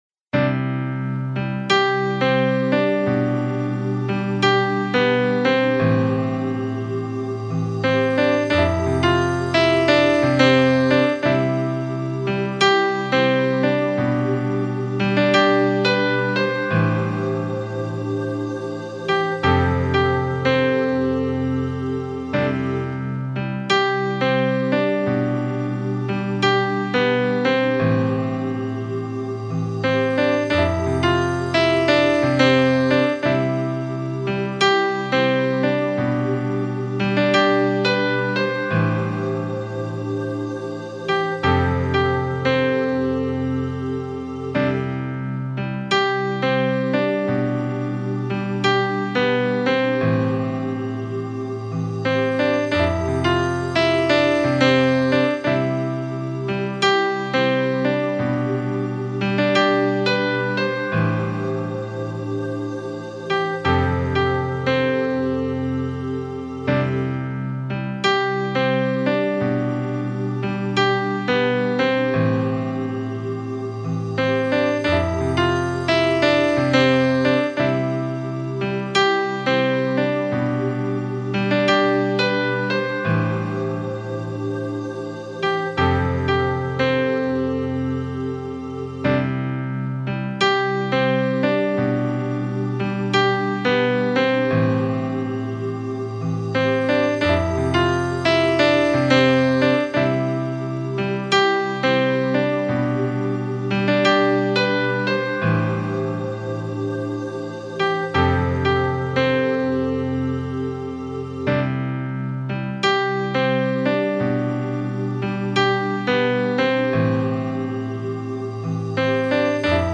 08.舒缓的钢琴曲 (1).mp3